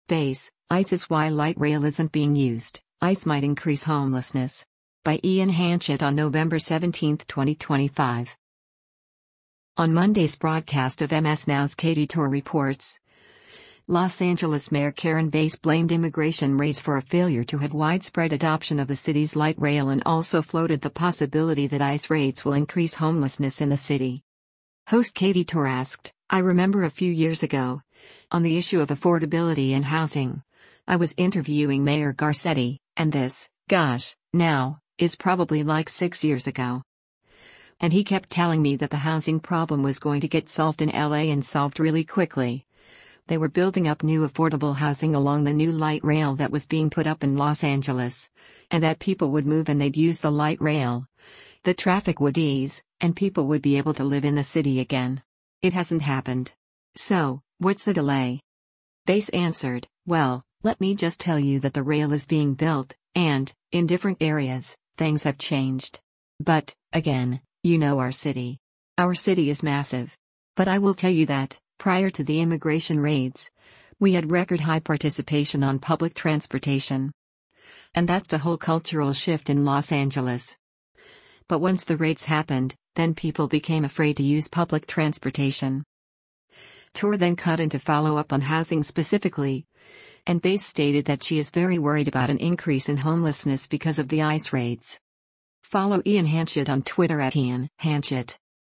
On Monday’s broadcast of MS NOW’s “Katy Tur Reports,” Los Angeles Mayor Karen Bass blamed immigration raids for a failure to have widespread adoption of the city’s light rail and also floated the possibility that ICE raids will increase homelessness in the city.
Tur then cut in to follow up on housing specifically, and Bass stated that she is “very worried about an increase in homelessness because of the ICE raids.”